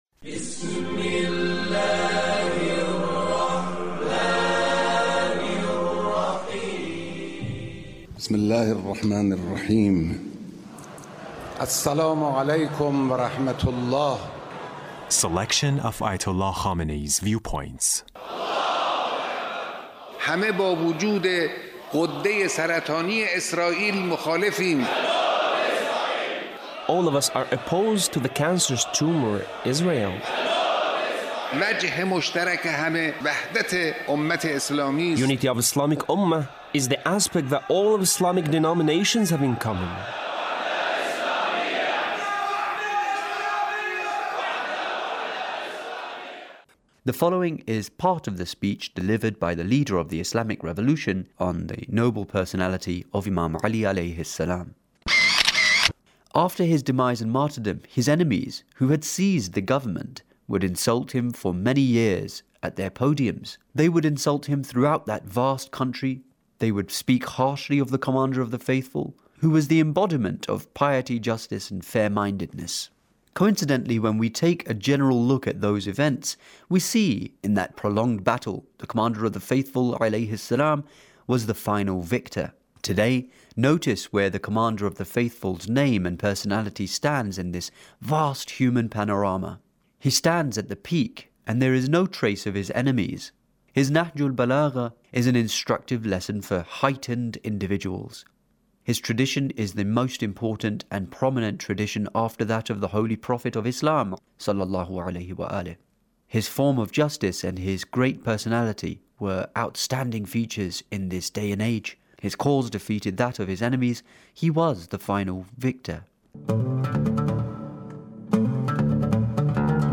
Leader's Speech on Imam Ali 's Character